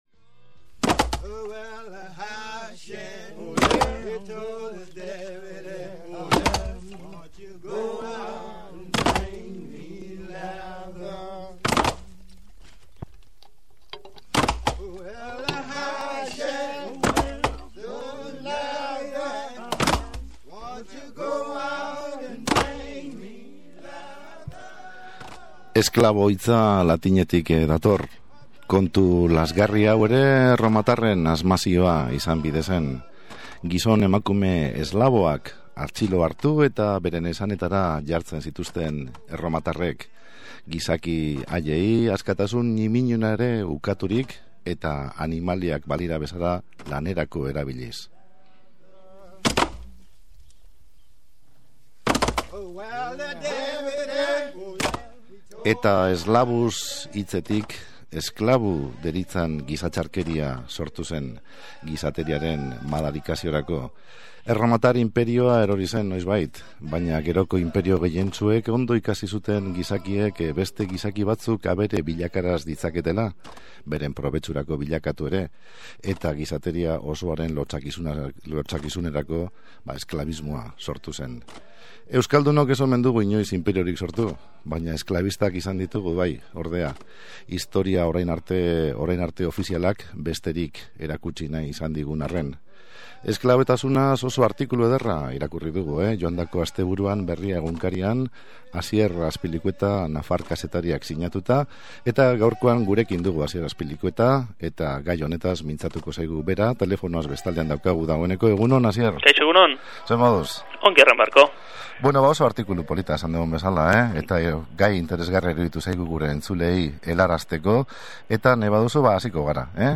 SOLASALDIA: Euskaldunak eta esklabotasuna